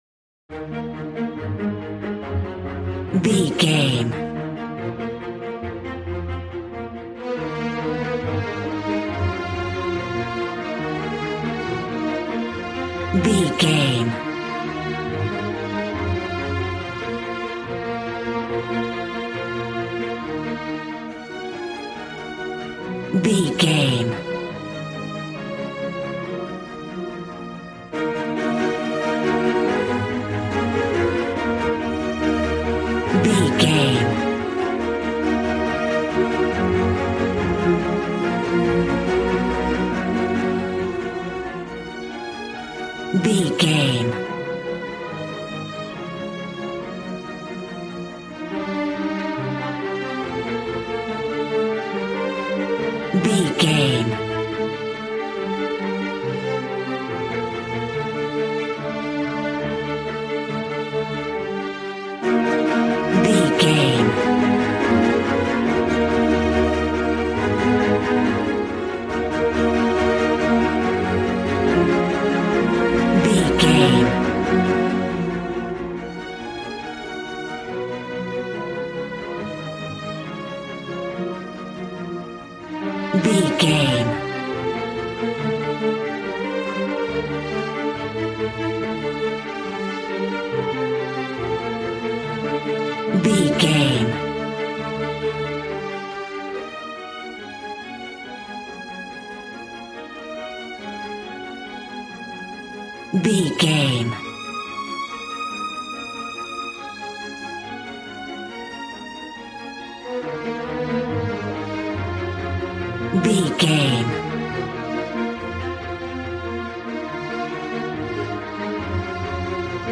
Regal and romantic, a classy piece of classical music.
Aeolian/Minor
B♭
regal
cello
violin
strings